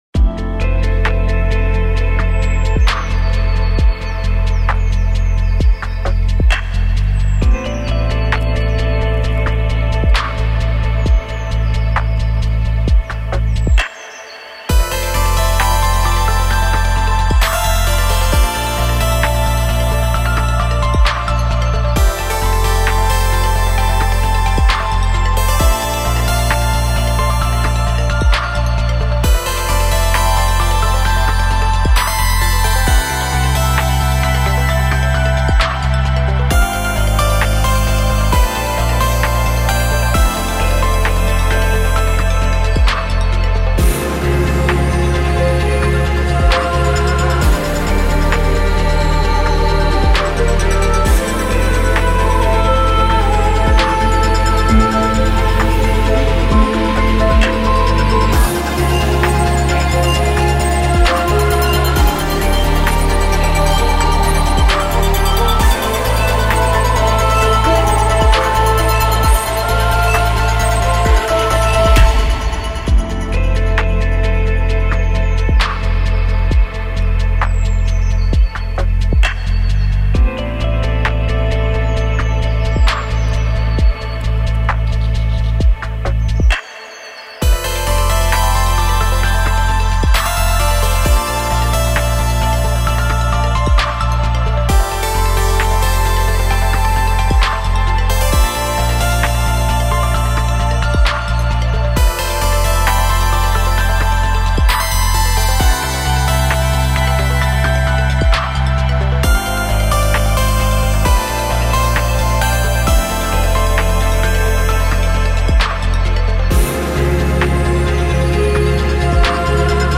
ジャンルアンビエント
BPM１３２
使用楽器ピアノ、ボイス
解説アンビエントなサウンドのフリーBGMです。
他の荒廃シリーズよりもどんよりした雰囲気を強調し、絶望感を際立たせております。